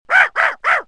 Small Dog Sound Button - Free Download & Play